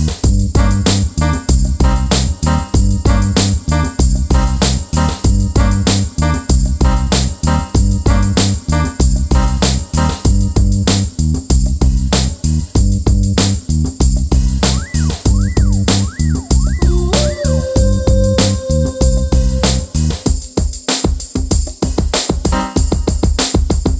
Duet Version Duets 4:01 Buy £1.50